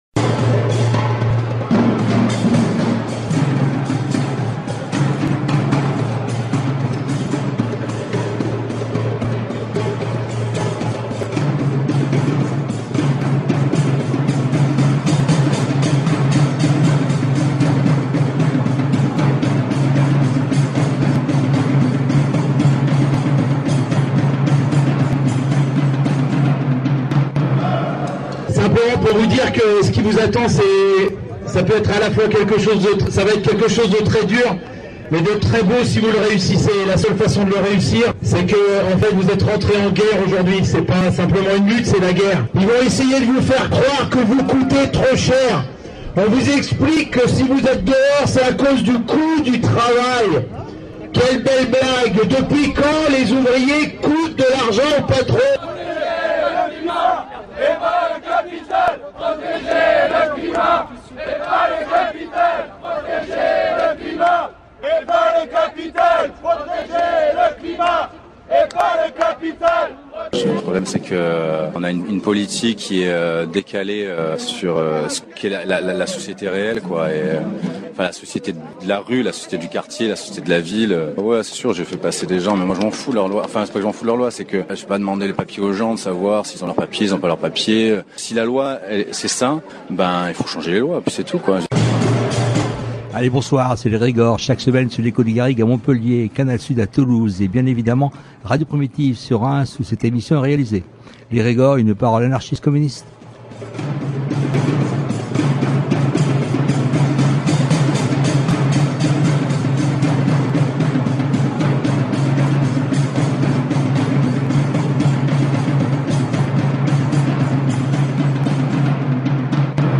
Nous vous diffusons donc dans une première partie d’émission, le montage réalisé à l’époque sur cette affaire et dans une deuxième partie d’émission, une partie des prises de paroles enregistrées lors du rassemblement du 5 juin dernier. classé dans : société Derniers podcasts Découvrez le Conservatoire à rayonnement régional de Reims autrement !